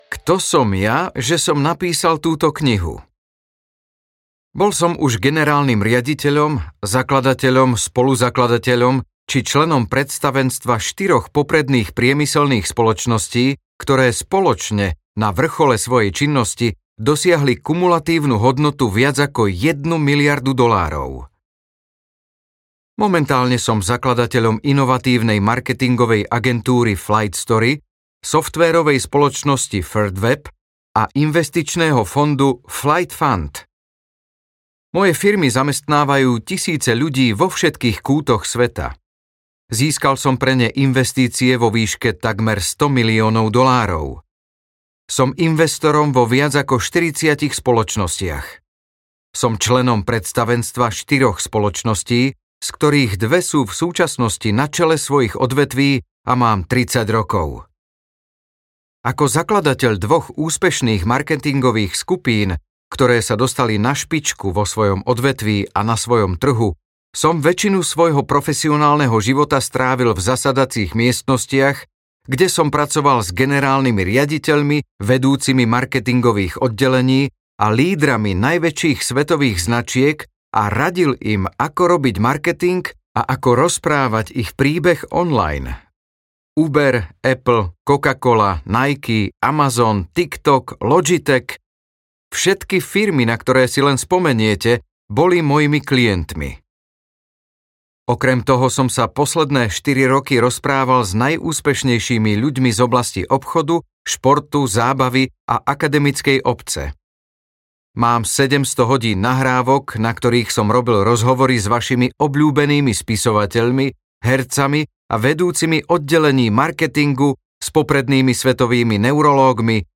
Audiokniha The Diary of a CEO - Steven Bartlett | ProgresGuru